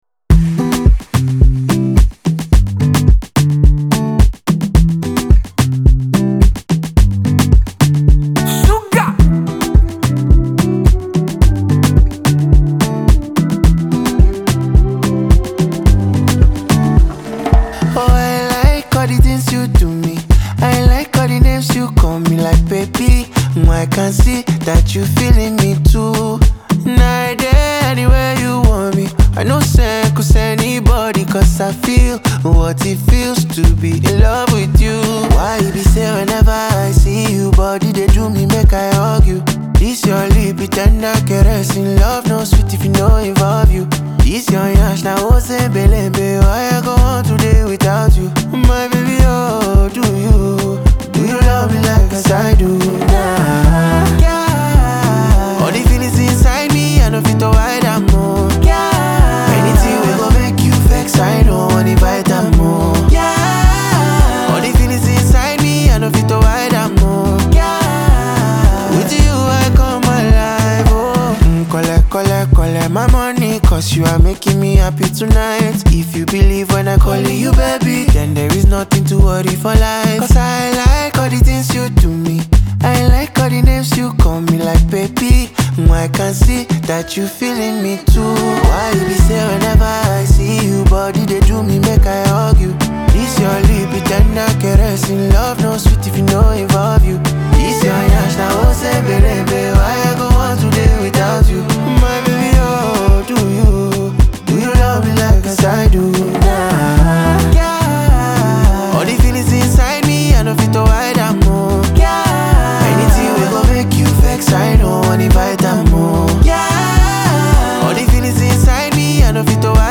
Afrobeats, infused with elements of R&B and Afropop